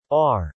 R_male.mp3